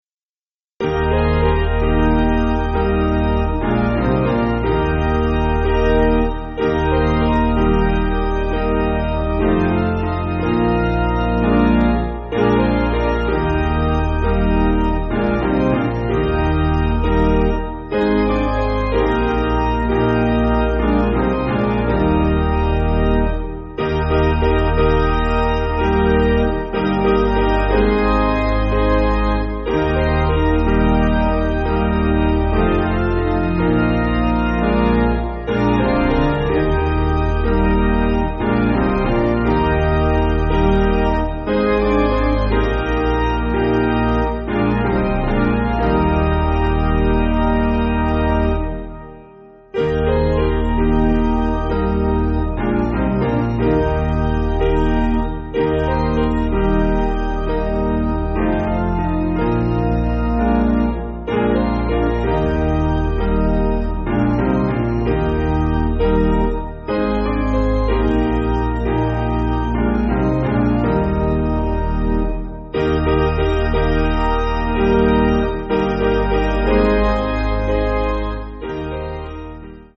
Basic Piano & Organ
(CM)   3/Eb